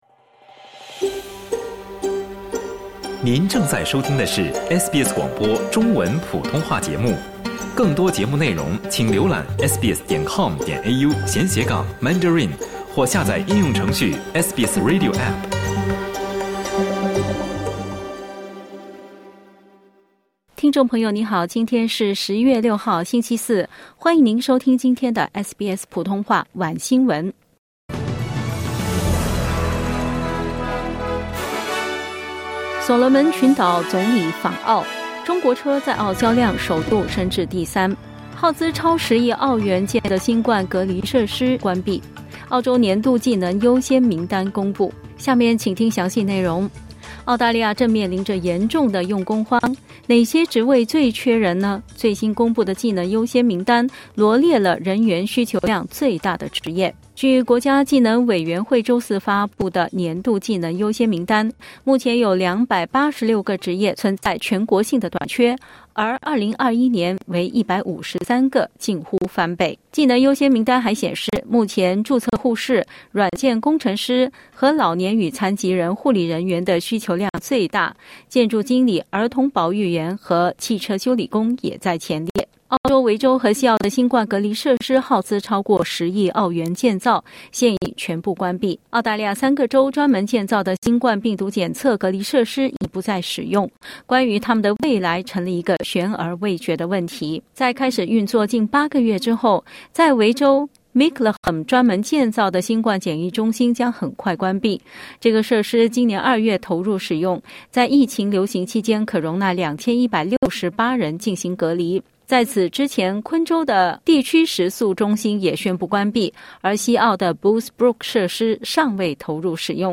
SBS普通话晚新闻（6/10/2022）